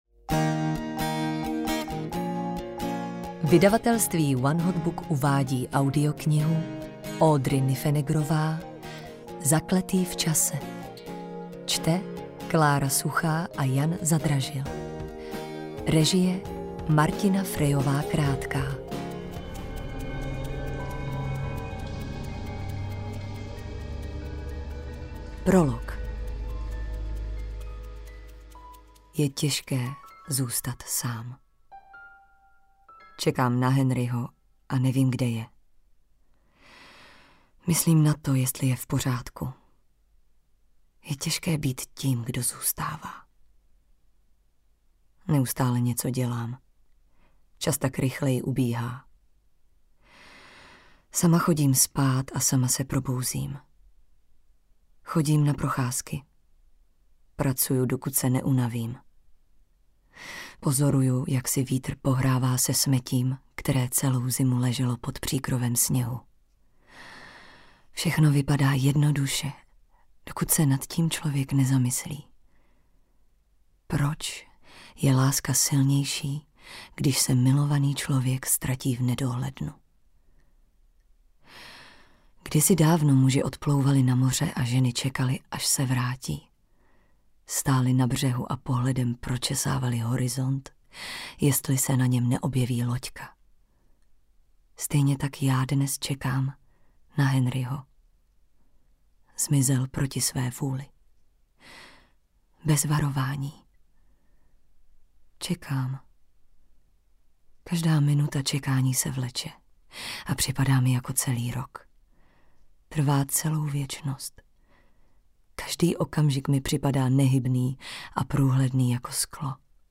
Zakletý v čase audiokniha
Ukázka z knihy